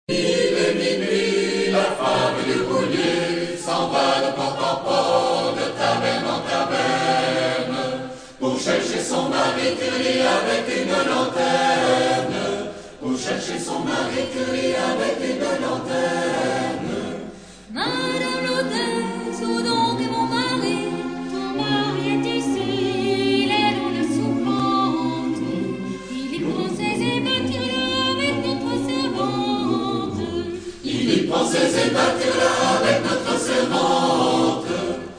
Chorale de l'ULB - Classiques
Harmonisation : Robert Ledent